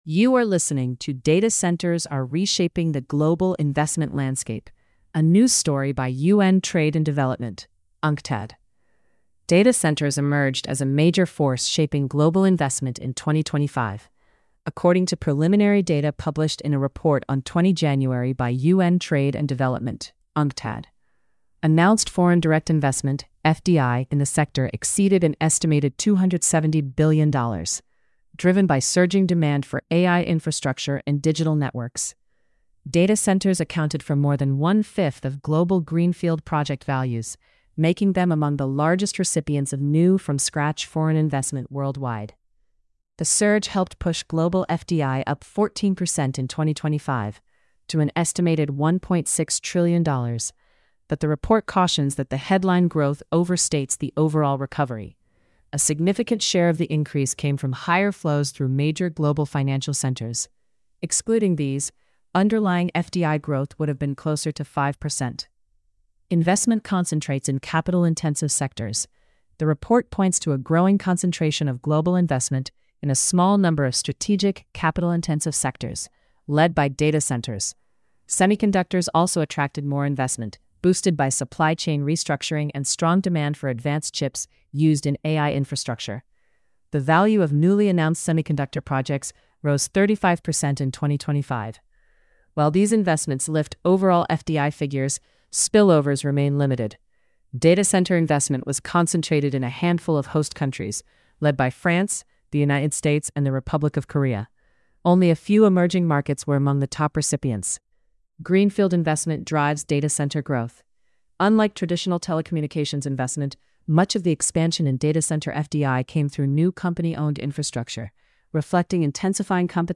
Narrated by AI. Inconsistencies may occur.